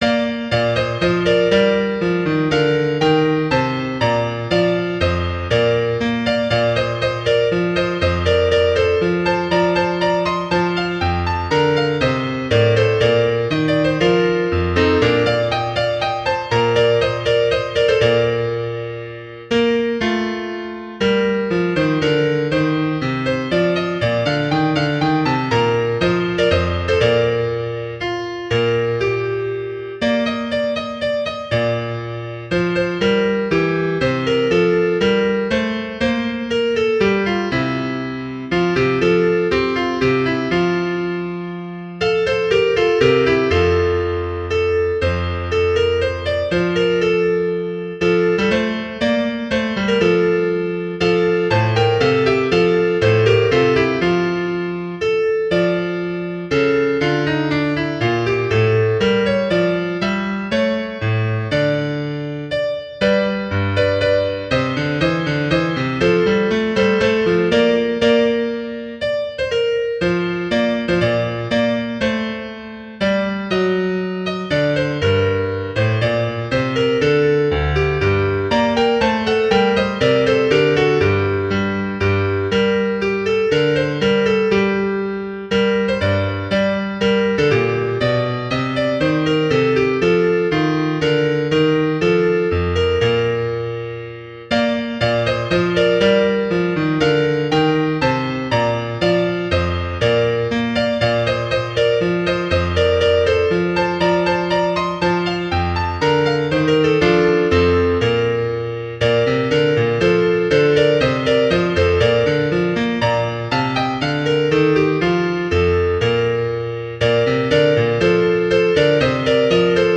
Key: B♭ Major
Text: Anthem